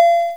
button.wav